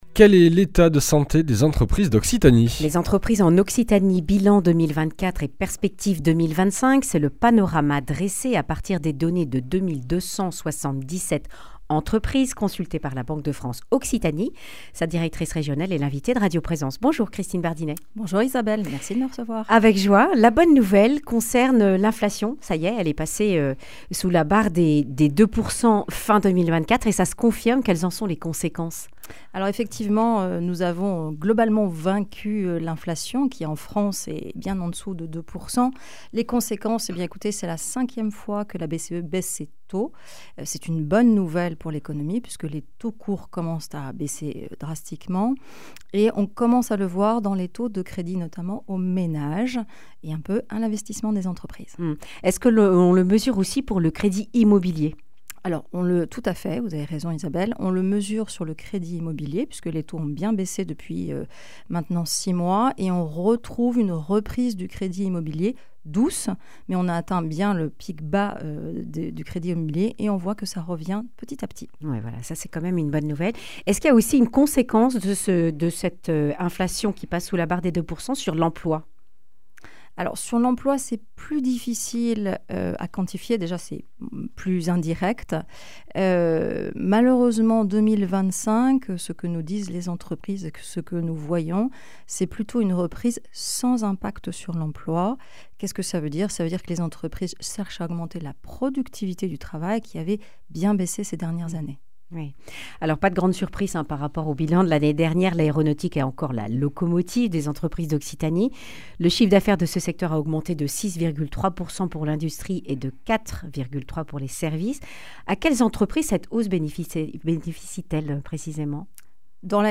Accueil \ Emissions \ Information \ Régionale \ Le grand entretien \ Quelles perspectives pour les entreprises d’Occitanie pour 2025 ?